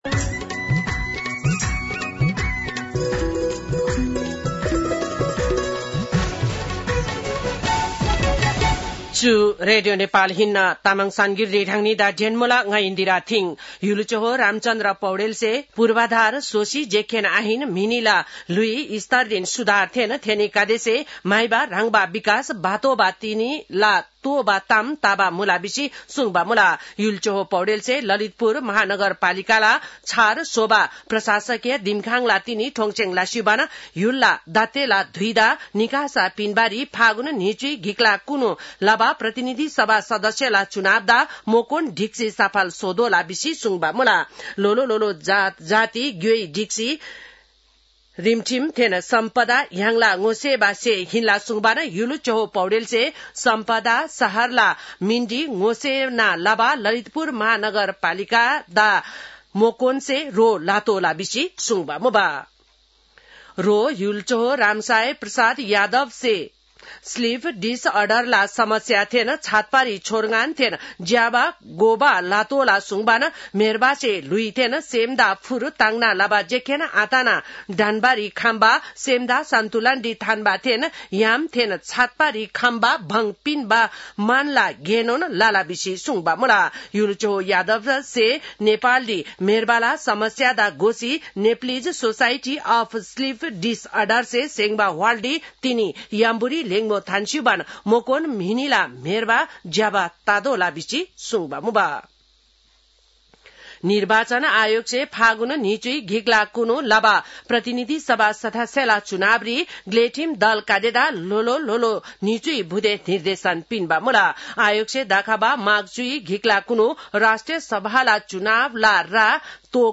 तामाङ भाषाको समाचार : २० मंसिर , २०८२